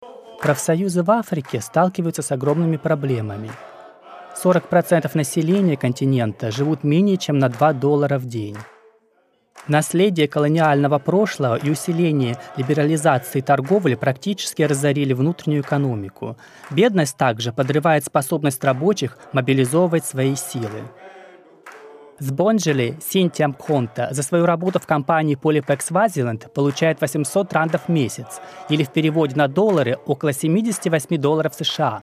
Russian Corporate Showreel
Male
Bright
Down To Earth
Friendly